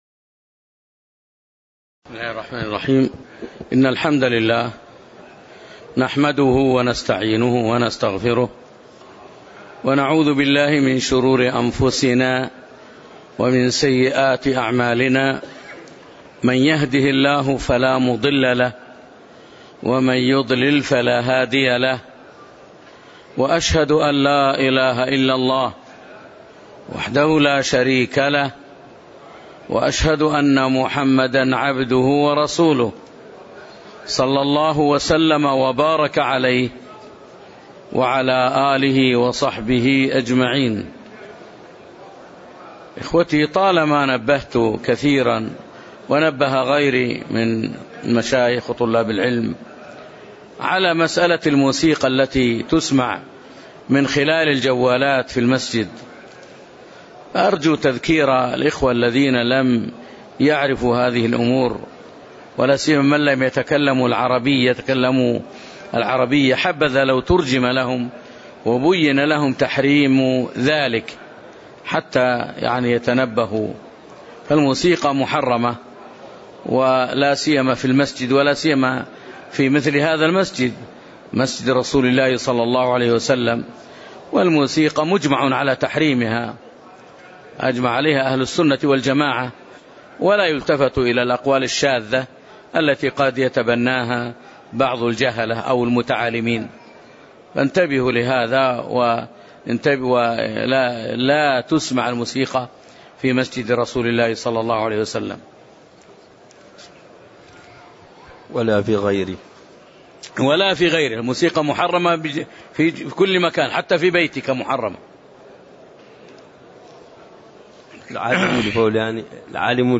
تاريخ النشر ٢٣ محرم ١٤٣٩ هـ المكان: المسجد النبوي الشيخ